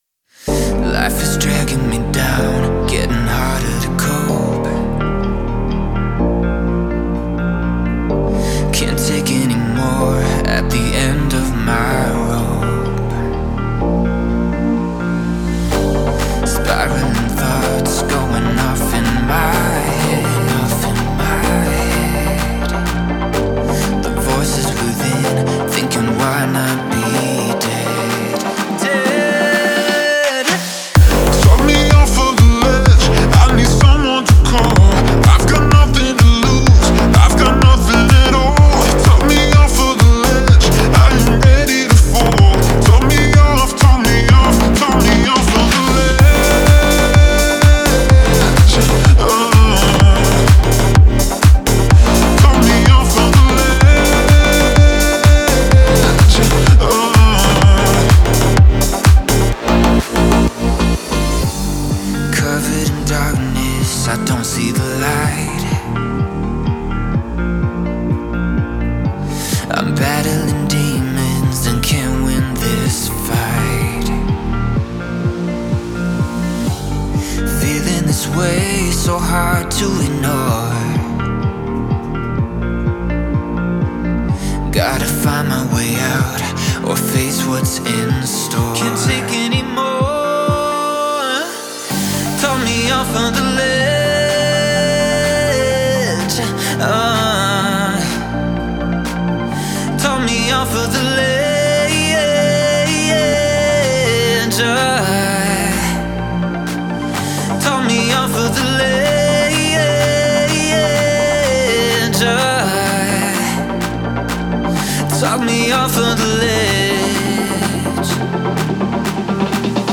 эмоциональный поп-трек с элементами инди